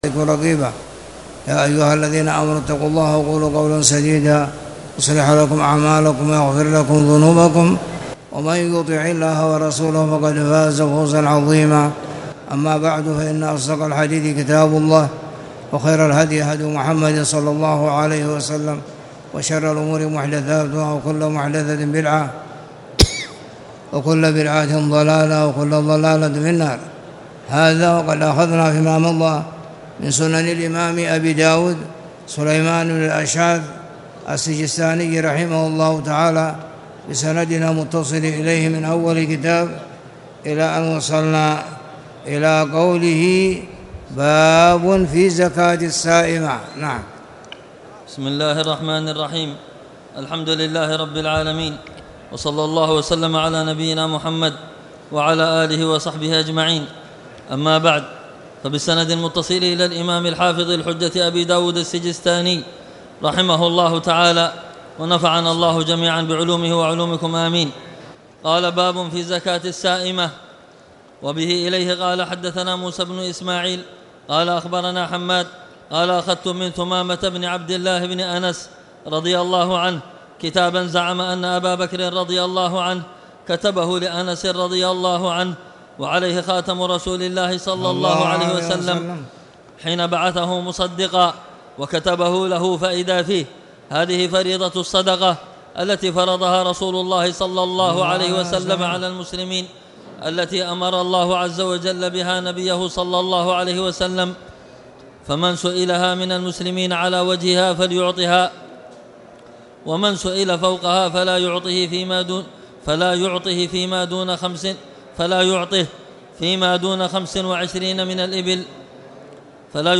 تاريخ النشر ٢٨ رجب ١٤٣٨ هـ المكان: المسجد الحرام الشيخ